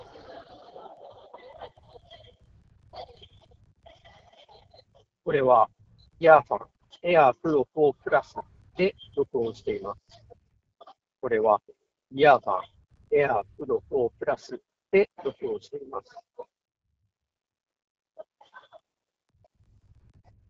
雑音を抑える効果はかなり高め。スピーカーから雑踏音をそこそこ大きなボリュームで流しながらマイクで収録してみました。
音声も若干こもり気味ではあるものの悪くありません。
earfun-air-pro-4plus-voice.m4a